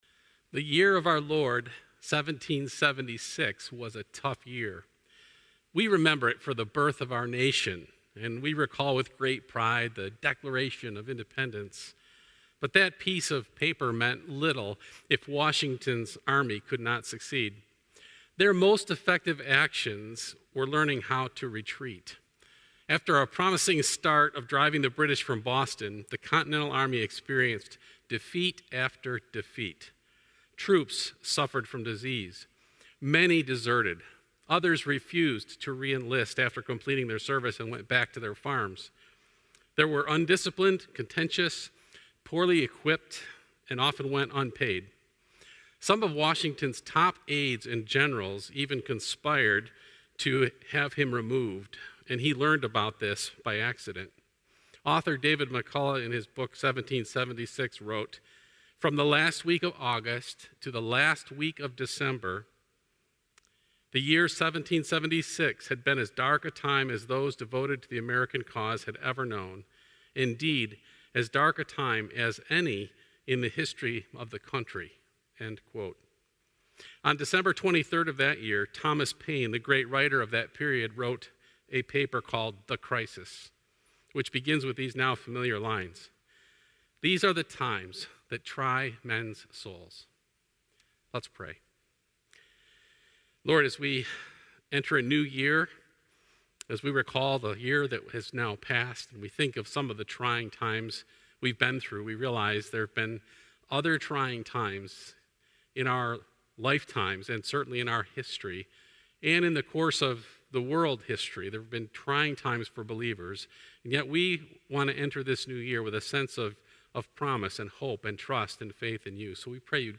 An online message on New Beginnings